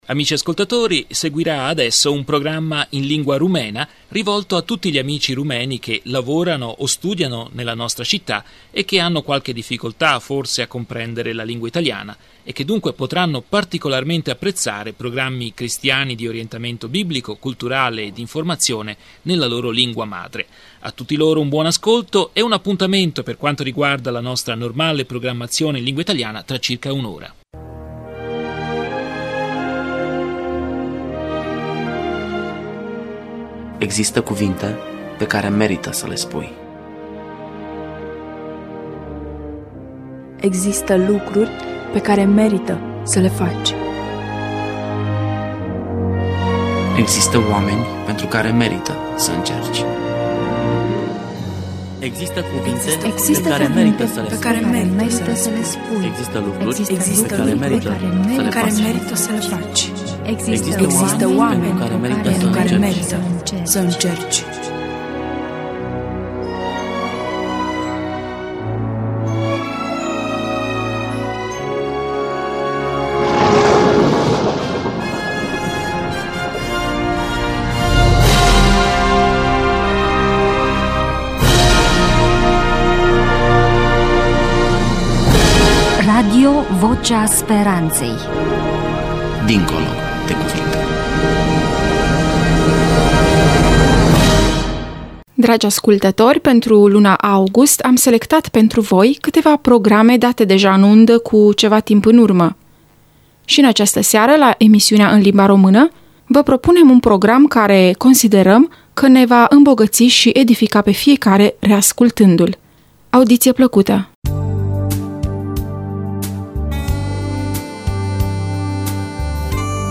Programma in lingua rumena a cura dello staff radiofonico della comunità avventista rumena di Firenze, trasmesso il 3 agosto 2009